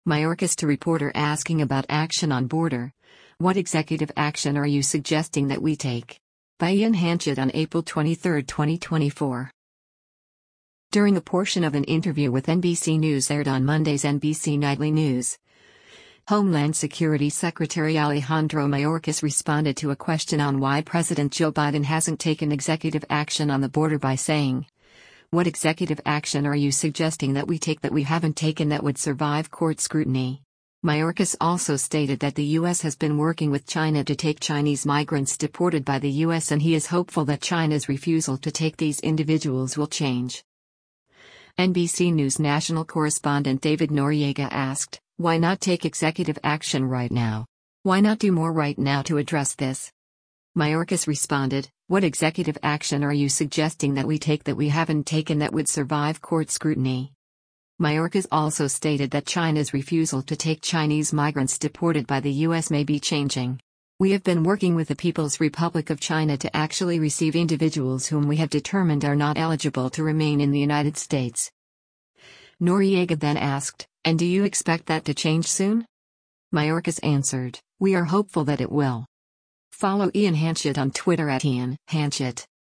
During a portion of an interview with NBC News aired on Monday’s “NBC Nightly News,” Homeland Security Secretary Alejandro Mayorkas responded to a question on why President Joe Biden hasn’t taken executive action on the border by saying, “What executive action are you suggesting that we take that we haven’t taken that would survive court scrutiny?”